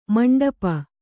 ♪ maṇḍapa